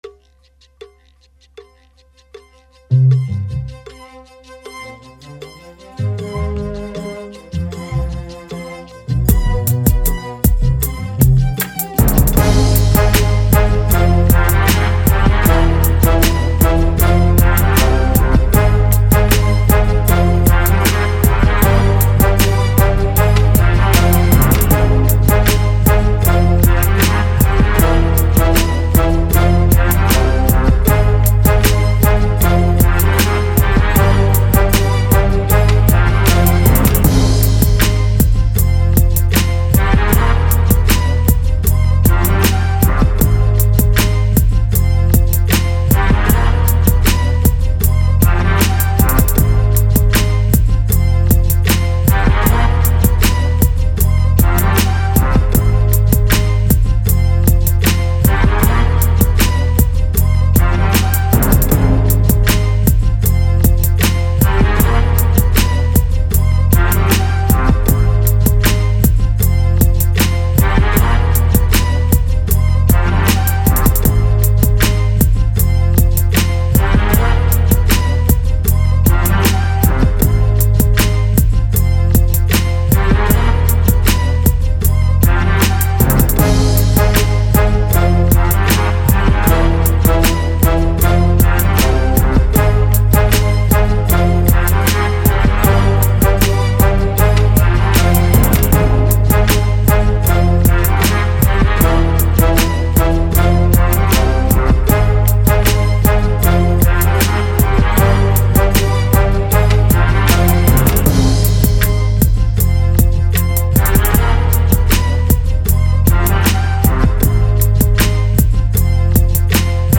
훅 부분의 멜로디를 새로 짰기 때문에 저는 그럭저럭 만족하고 있습니다 ㅜㅎㅎ